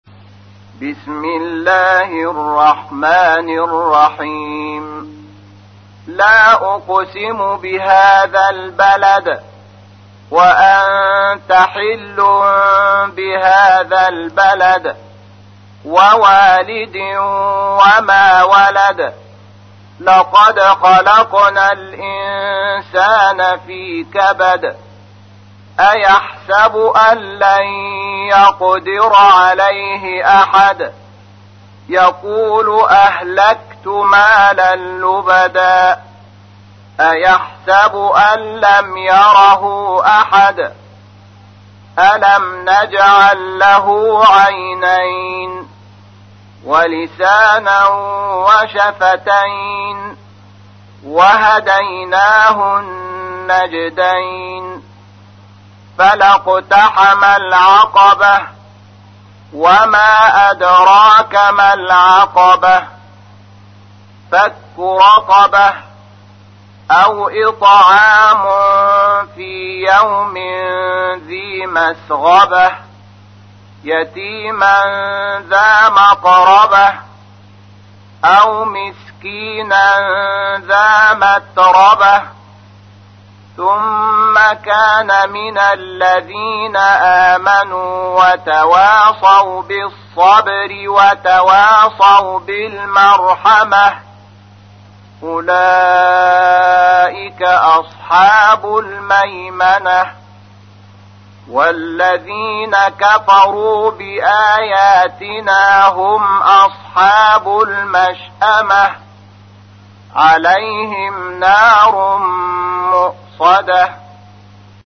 تحميل : 90. سورة البلد / القارئ شحات محمد انور / القرآن الكريم / موقع يا حسين